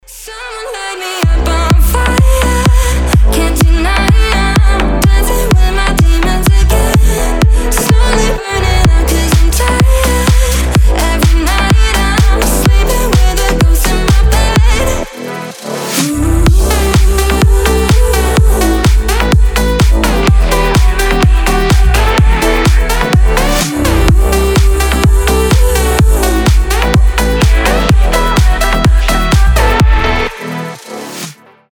красивый женский голос
slap house